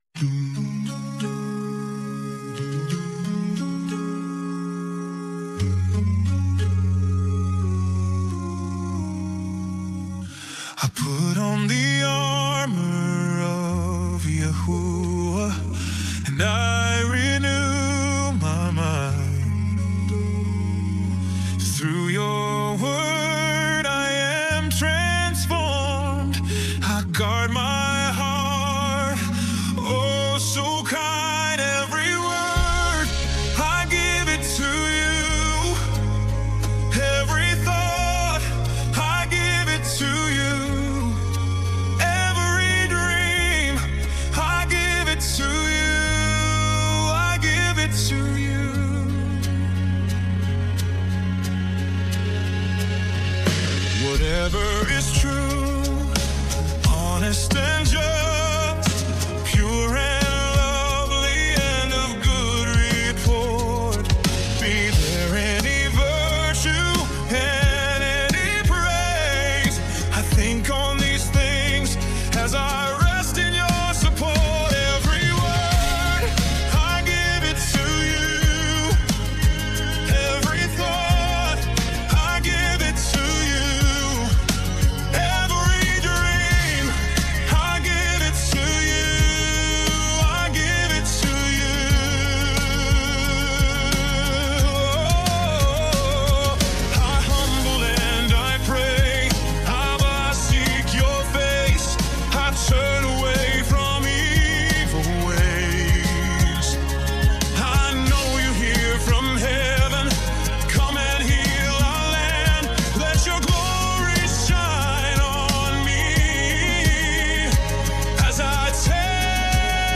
Original worship music including
[ Practice Track – Lower Key ]